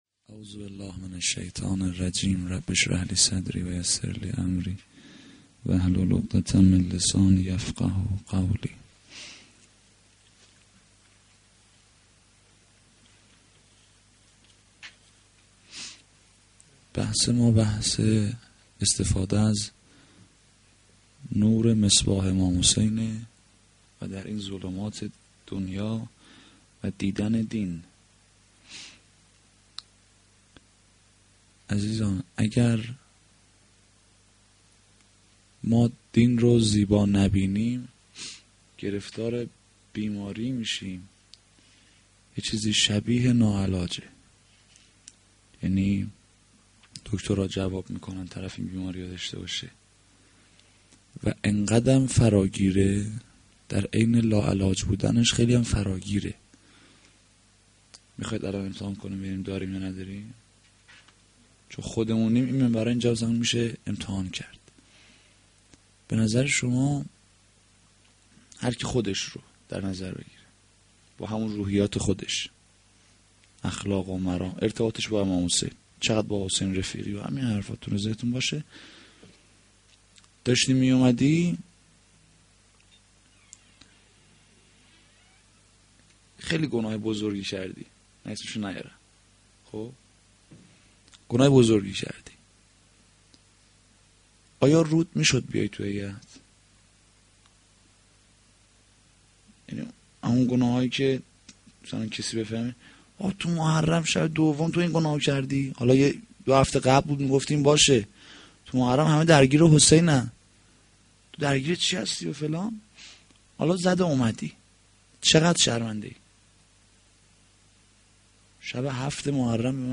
sh-2-moharram-92-sokhanrani.mp3